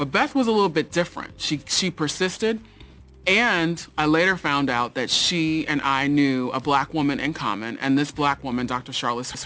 example_female_voice_9_seconds.wav